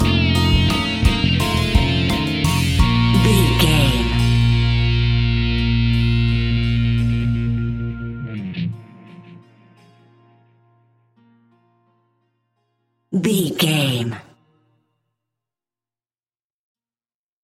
In-crescendo
Thriller
Aeolian/Minor
ominous
eerie
Horror Pads
Horror Synths
Horror Synth Ambience